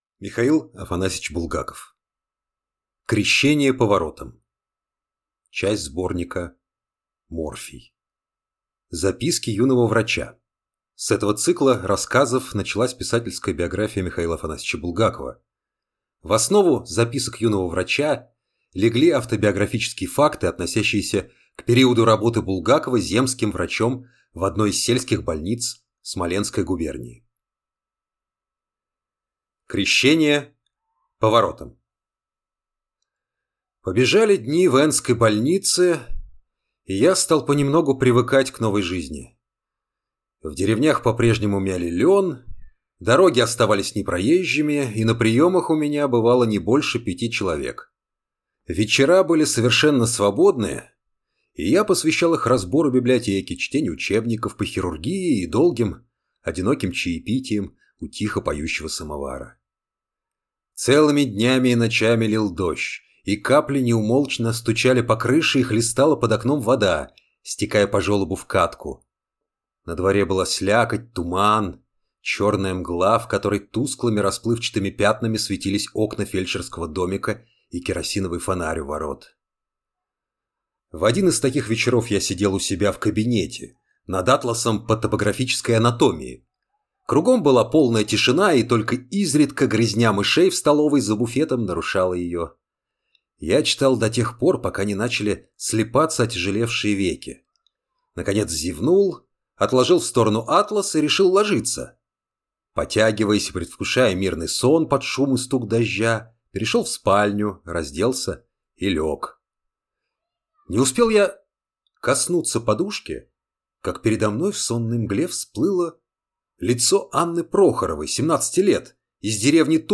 Аудиокнига Крещение поворотом | Библиотека аудиокниг